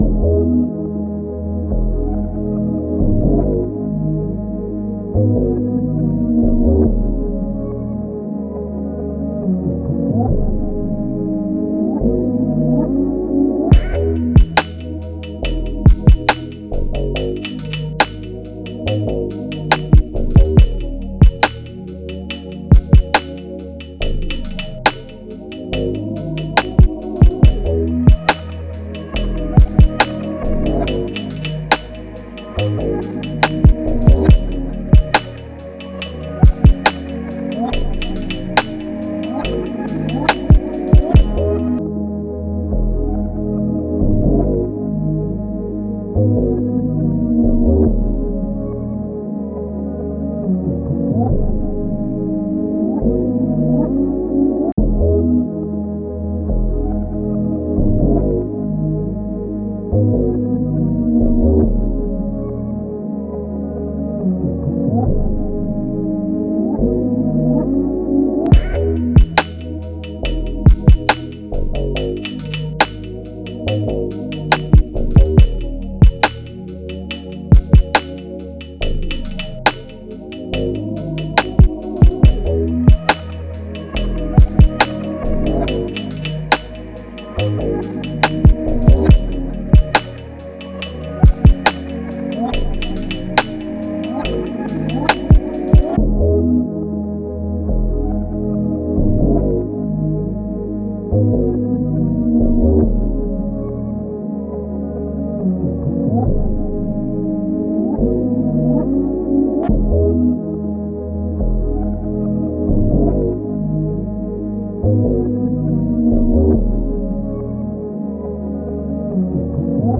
R&B
Ab Minor